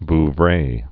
(v-vrā)